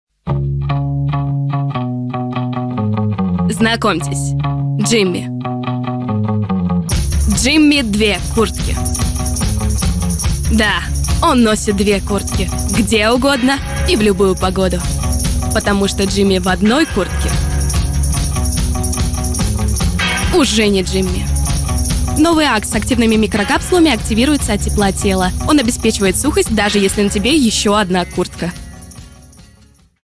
Пример звучания голоса
Жен, Рекламный ролик
Студийный микрофон FiFine AM8